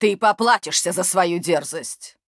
Те же самые фразы, но уже в исполнении актрисы озвучивания.
VO_HERO_13_Threaten_05.wav